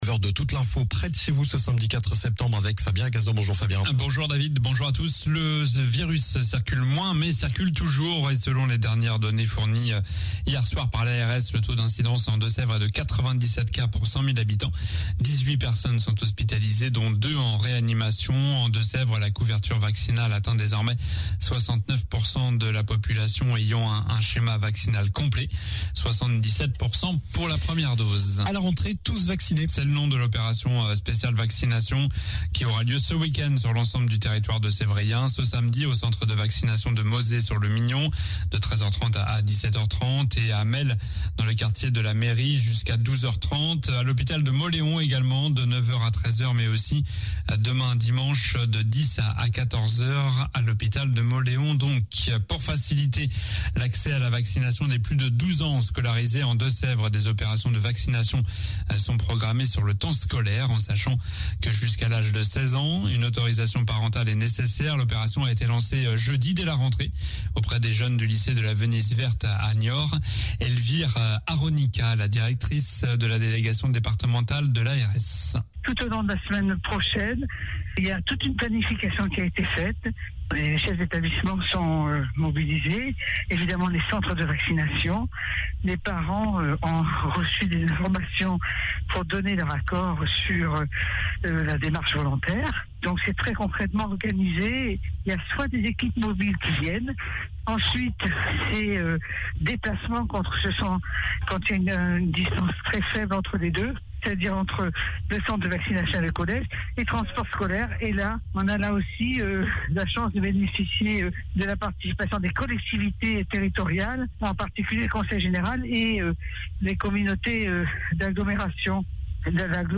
Journal du samedi 4 septembre (matin)